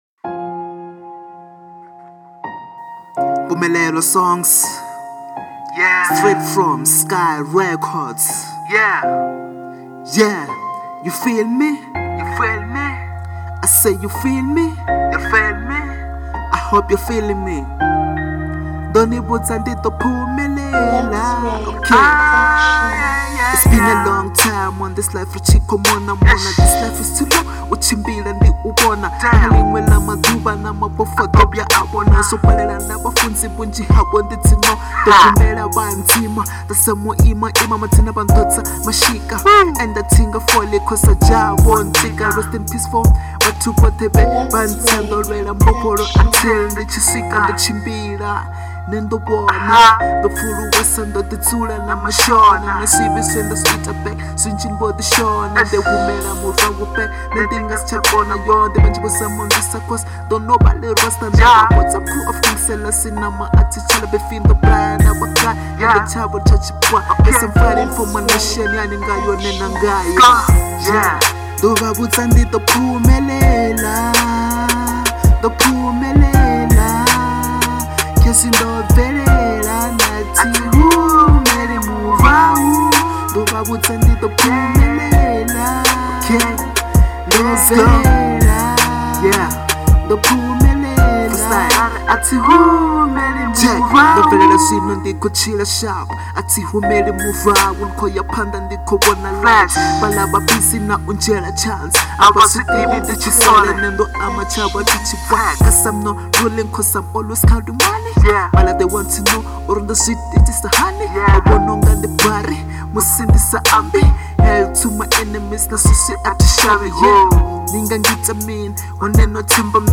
03:14 Genre : Hip Hop Size